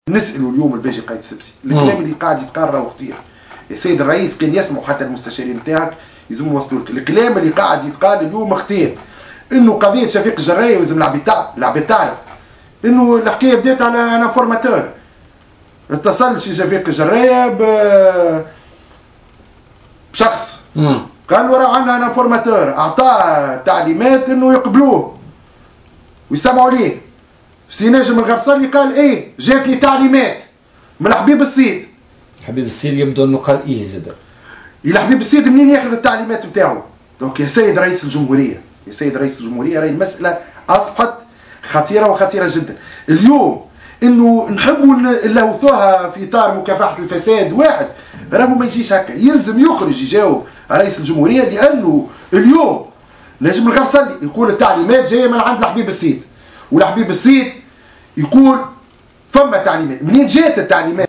وجه الناشط السياسي محمود البارودي ضيف بولتيكا اليوم الإثنين 13 نوفمبر 2017 رسالة الى رئيس الجمهورية الباجي قايد السبسي لمصارحة الشعب التونسي بحقيقة التعليمات التي أصدرت في شأن شفيق الجراية لإيقافه وسط تضارب الأخبار بشأن الجهة التي أصدرت التعليمات.